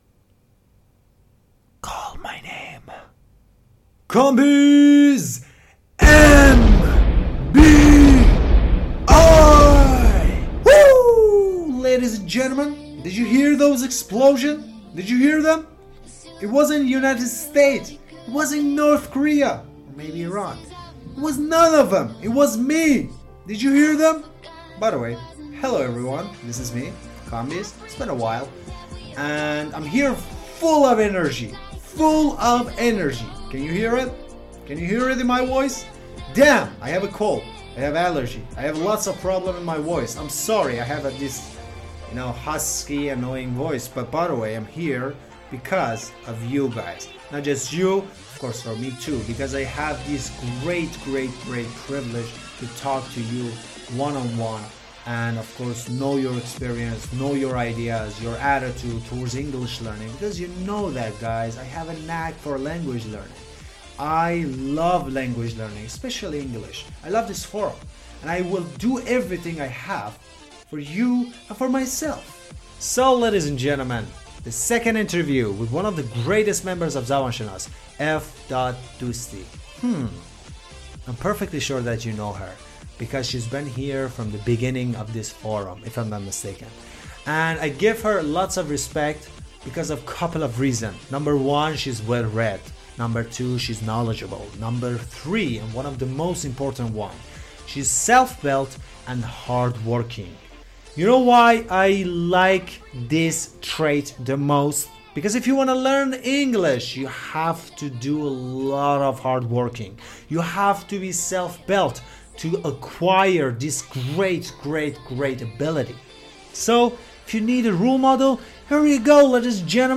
دو تا مصاحبه تا الان در تالار پخش شده.